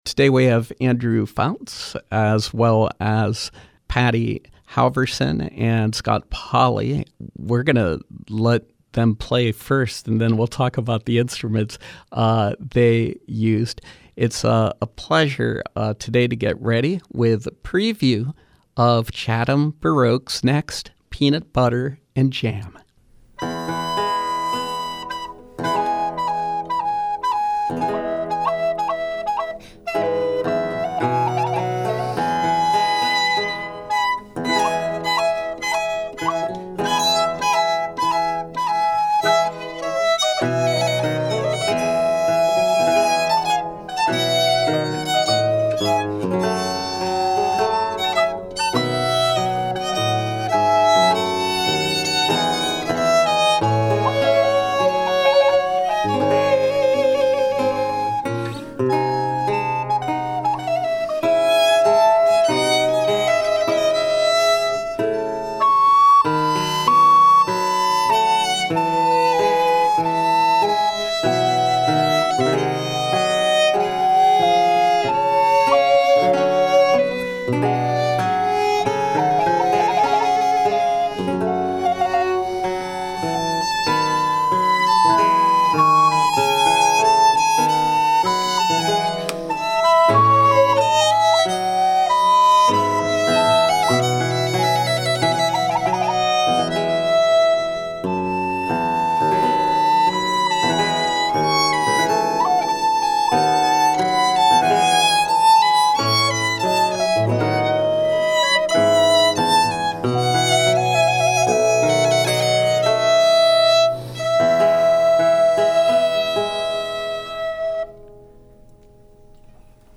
In-Studio Pop Up: Peanut Butter and Jam Sessions, Chatham Baroque
violin
viola da gamba
theorbo and baroque guitar